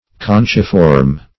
Search Result for " conchiform" : The Collaborative International Dictionary of English v.0.48: Conchiform \Con"chi*form\, a. [Conch + -form.]
conchiform.mp3